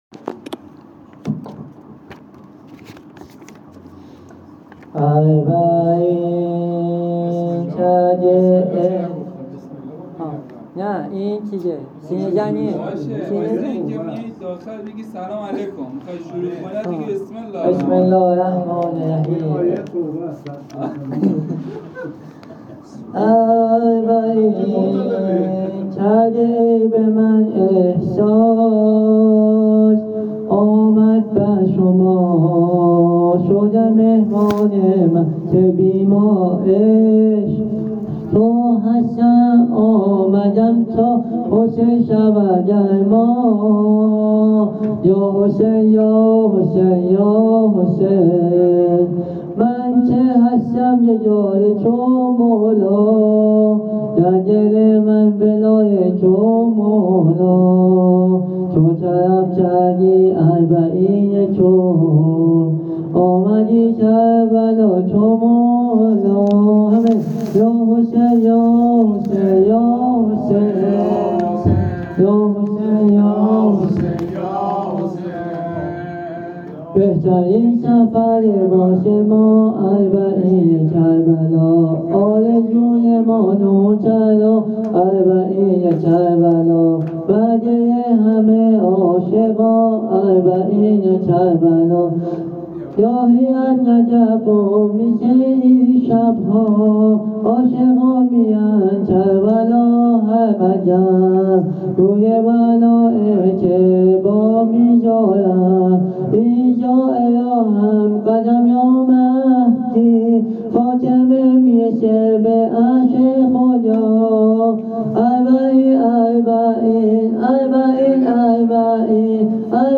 زمینه اربعین حسینی
هیئت ابافضل العباس امجدیه تهران